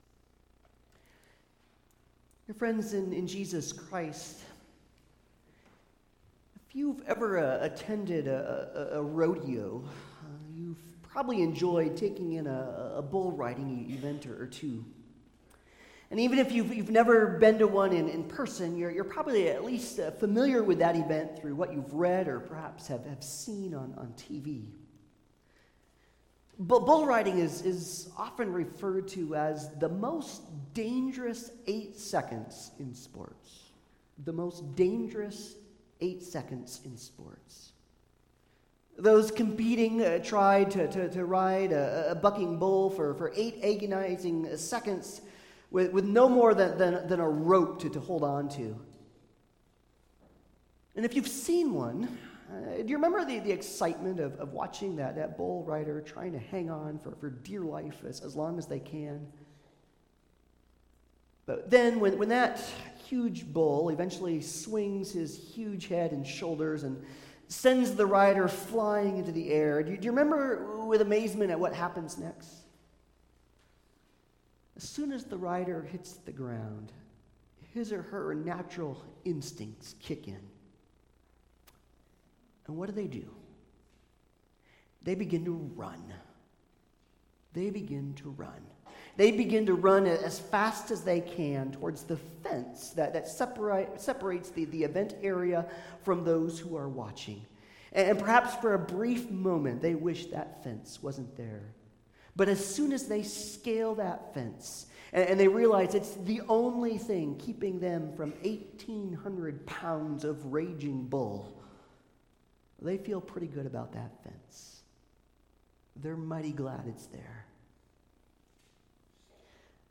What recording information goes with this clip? Commandments Passage: Exodus 20:14, Genesis 2:18-25, Matthew 5:27-30, 1 Corinthians 6:18-20 Service Type: Sunday Service